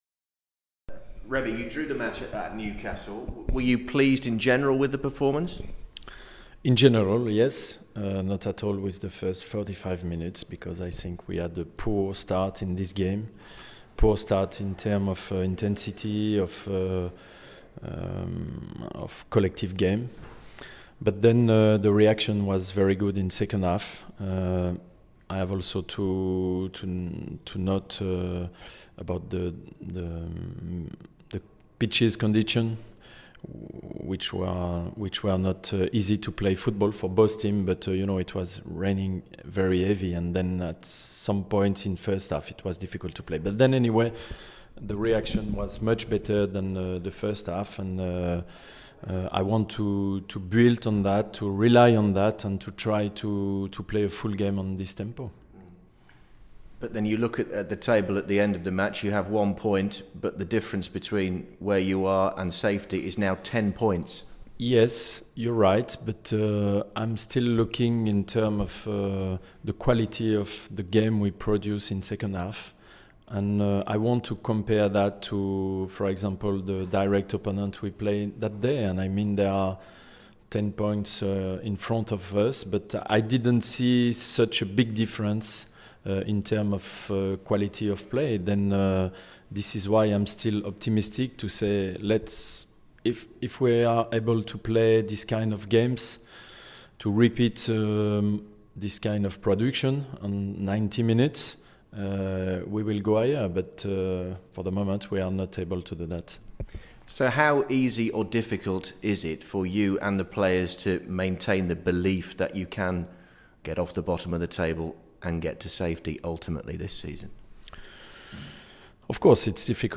was at the Villa pre West Ham Radio media conference with boss Remi Garde